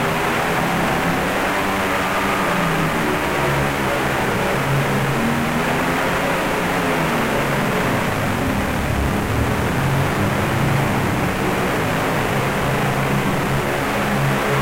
static.ogg